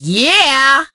penny_kill_vo_01.ogg